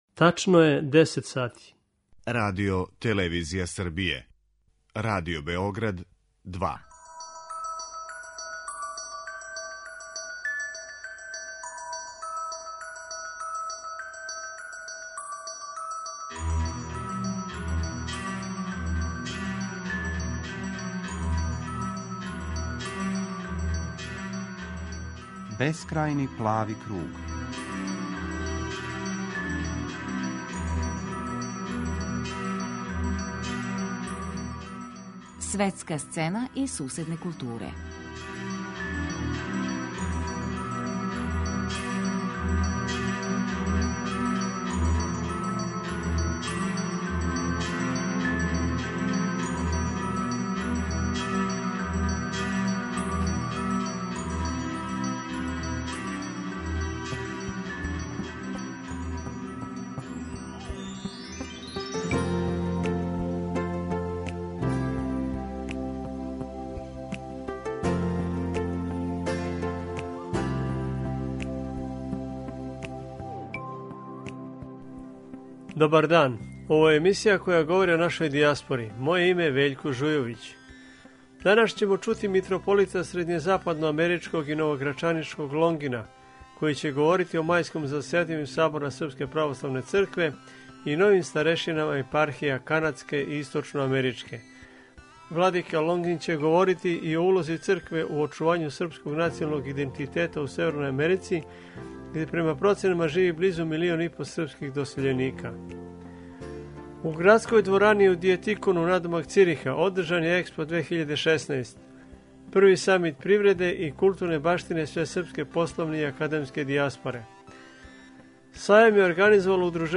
Емисија о дијаспори